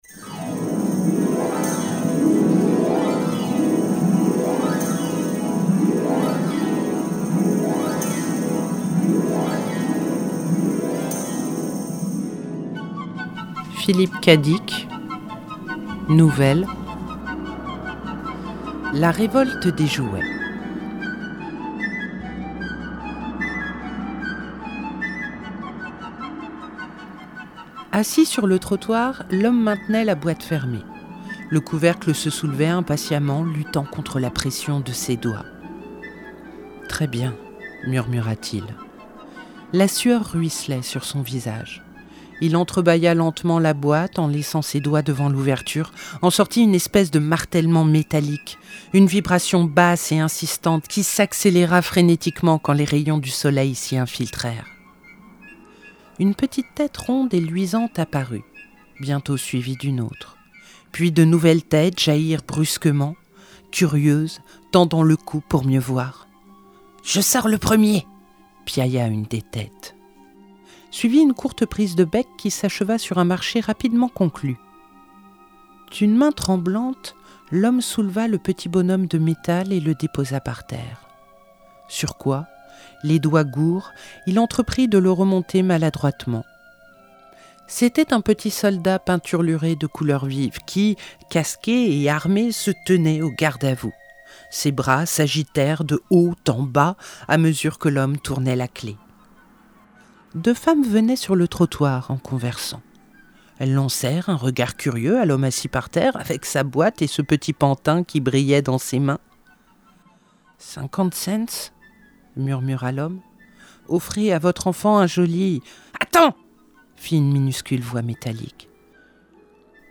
🎧 La Révolte des jouets – Philip K. Dick - Radiobook
Nouvelle (26:40)